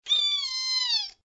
audio: Converted sound effects
AV_rabbit_howl.ogg